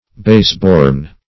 Baseborn \Base"born`\, a.